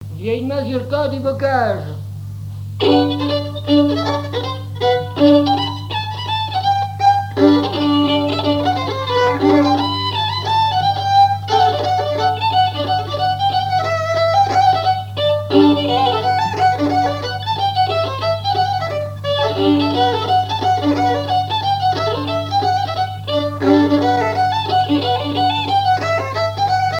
danse : mazurka
Répertoire du violoneux
Pièce musicale inédite